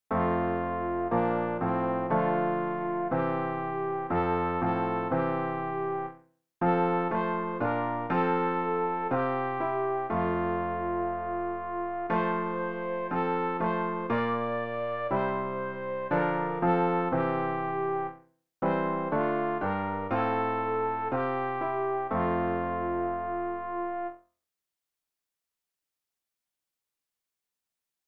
sopran-rg-15-der-herr-ist-mein-getreuer-hirt.mp3